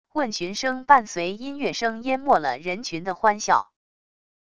问询声伴随音乐声淹没了人群的欢笑wav音频